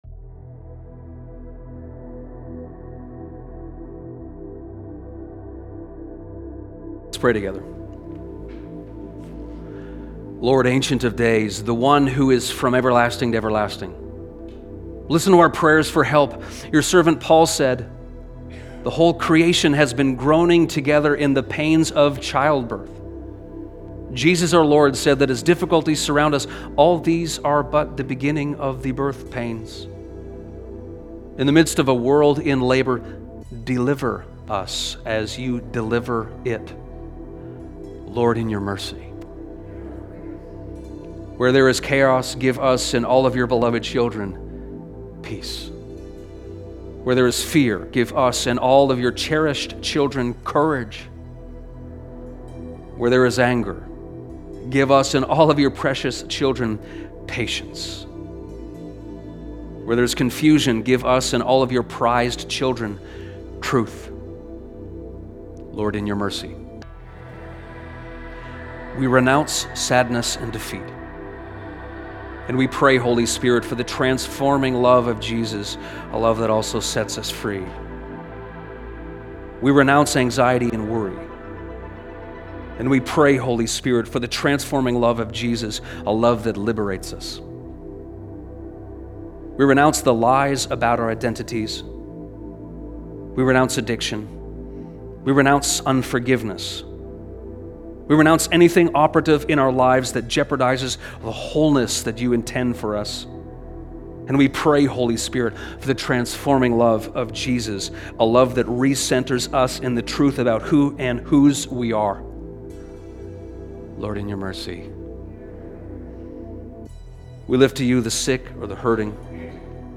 pastoral prayer
from our service on February 22, 2026.